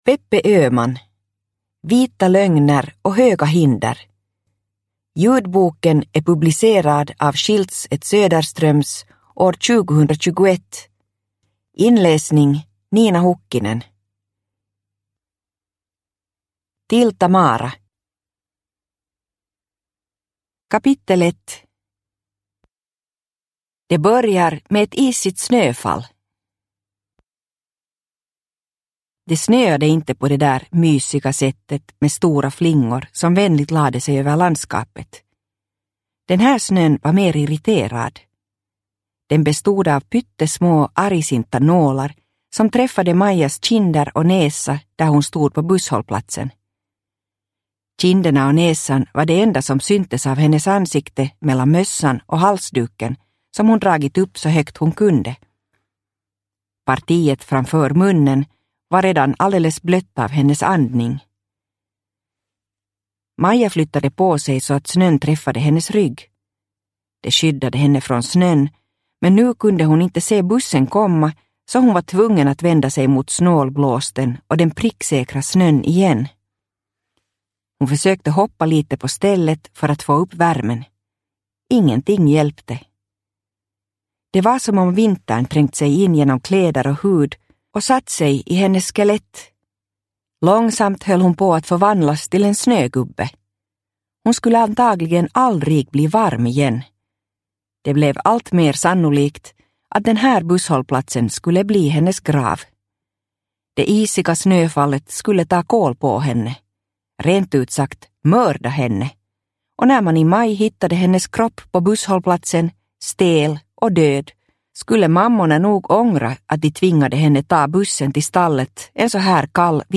Vita lögner och höga hinder – Ljudbok